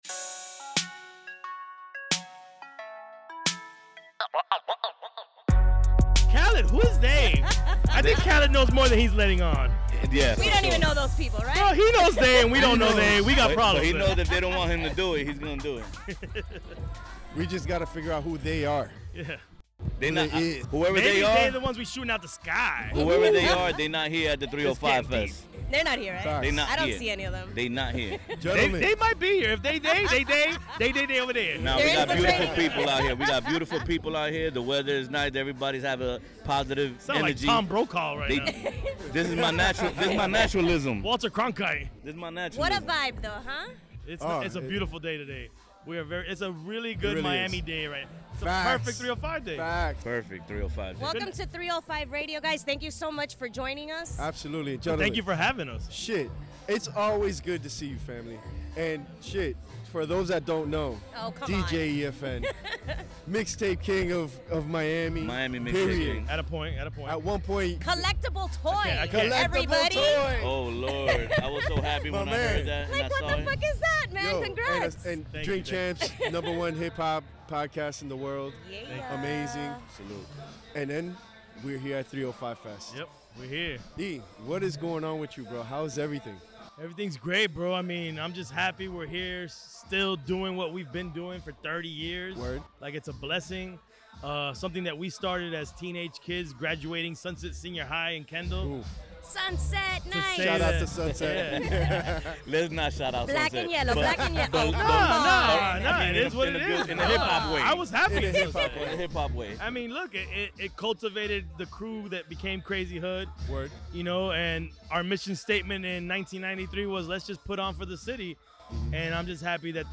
We were recording live at 305 Fest at Oasis in Wynwood.